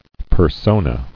[per·so·na]